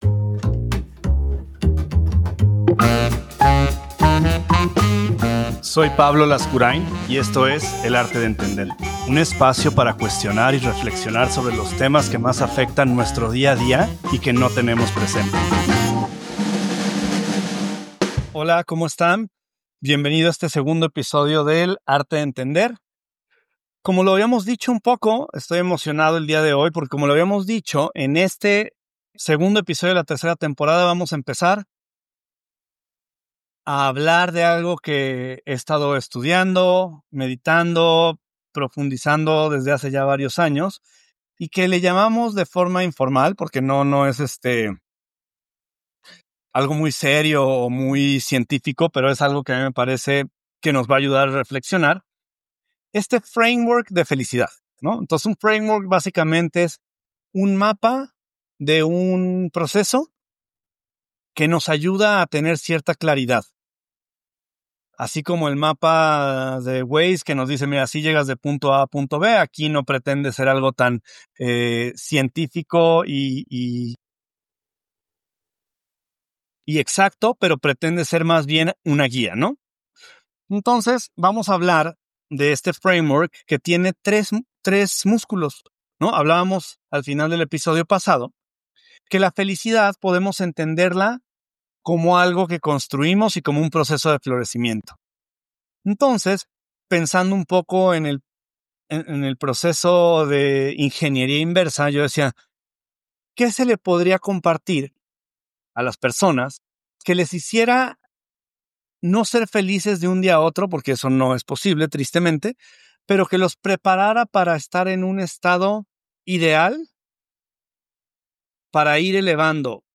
El Arte de Entender es un podcast sin cortes ni ediciones grabado en una sola toma, enfocado en compartir distintas formas de ver los temas que nos retan todos los días de forma práctica y profunda. Un espacio para cuestionar y reflexionar sobre los temas que más afectan nuestro día a día y que no tenemos presentes.